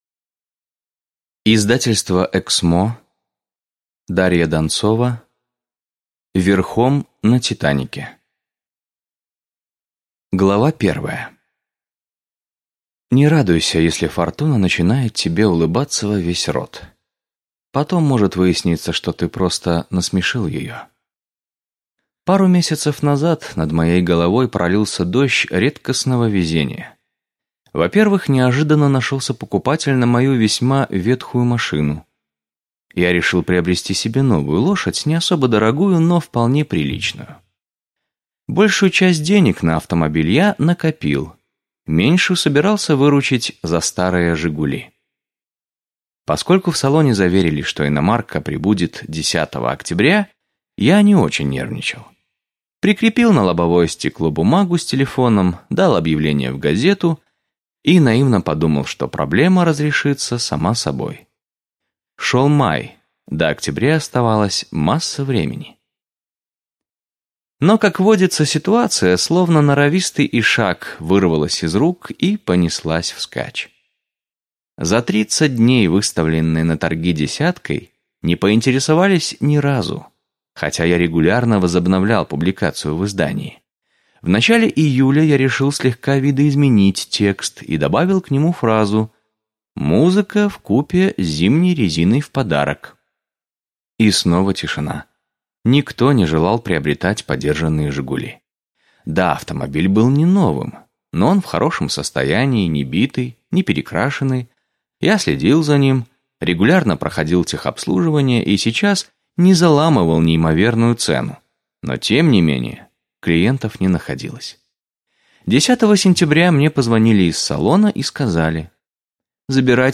Аудиокнига «Верхом на «Титанике»» в интернет-магазине КнигоПоиск ✅ в аудиоформате ✅ Скачать Верхом на «Титанике» в mp3 или слушать онлайн